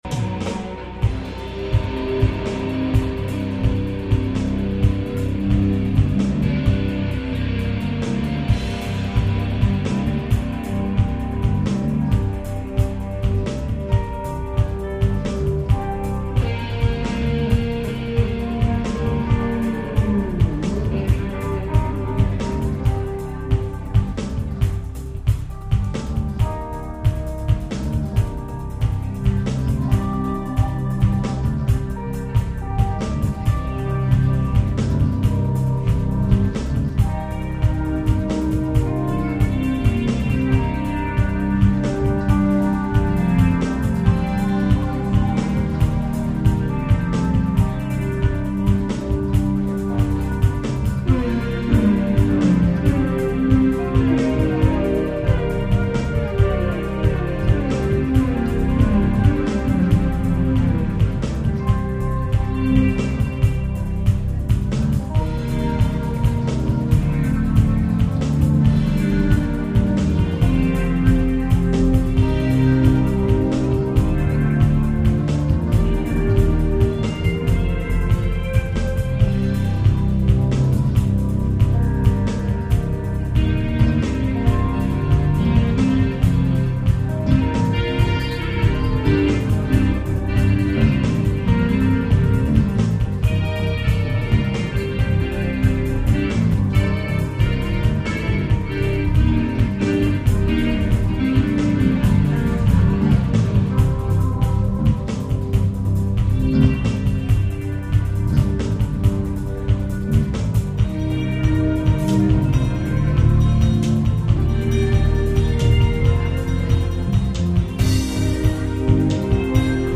"Live" at the Metaphor Cafe in Escondido, CA
keyboard
Guitar
Bass
Electronic Drums